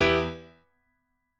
admin-leaf-alice-in-misanthrope/piano34_1_020.ogg at main